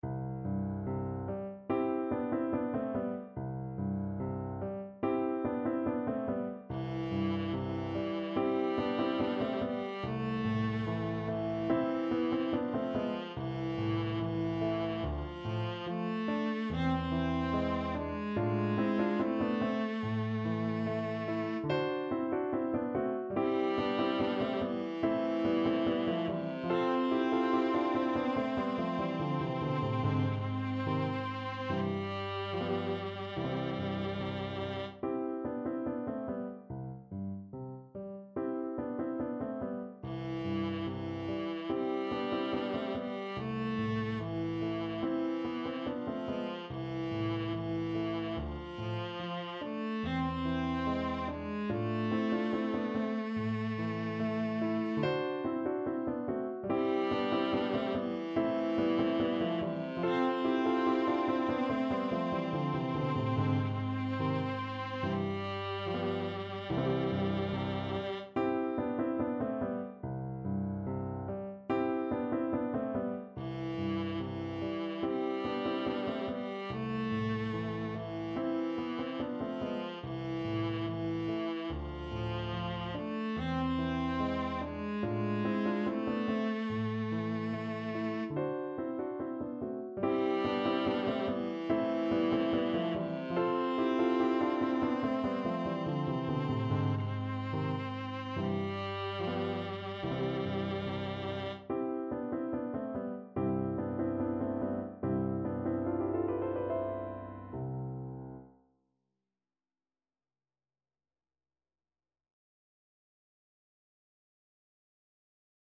Time Signature: 4/4
Tempo Marking: Allegretto ( =72)
Score Key: C major (Sounding Pitch)
Instrument: Viola